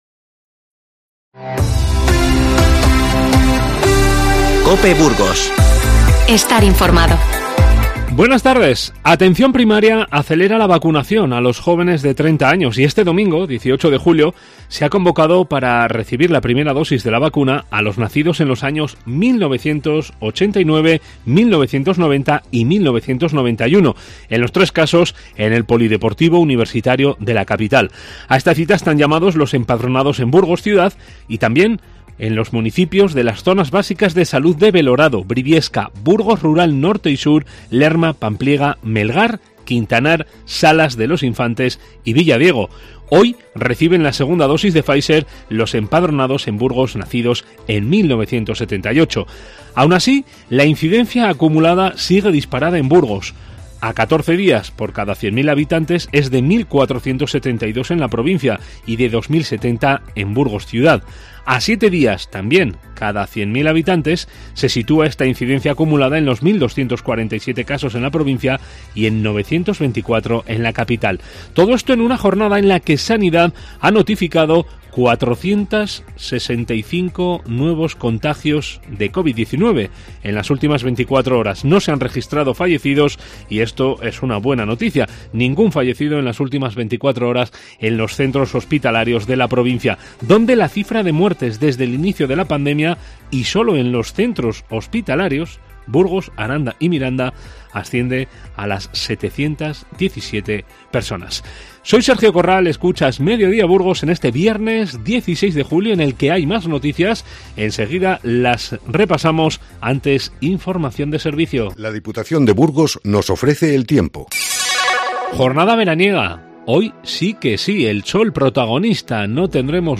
INFORMATIVO Mediodía 16-07-21